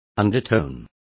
Complete with pronunciation of the translation of undertone.